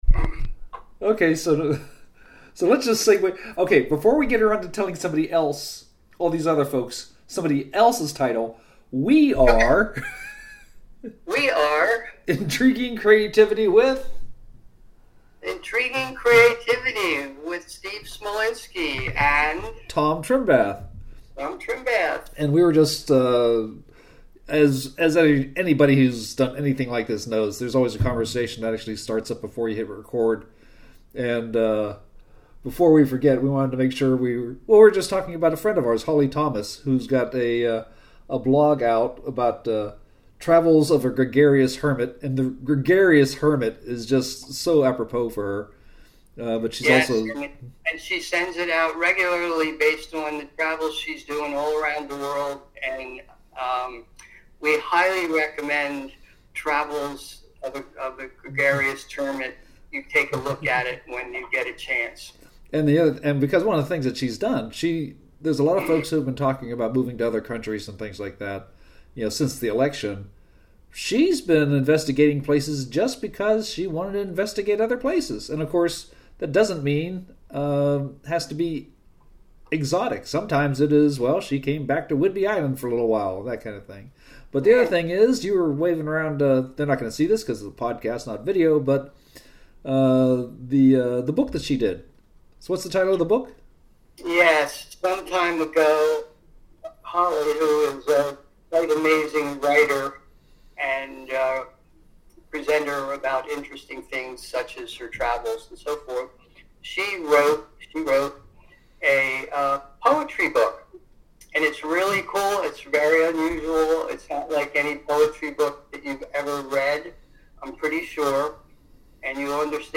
Let that conjure images of coffee in a diner, whiskey in some bar, or, in reality, we two guys recording this episode.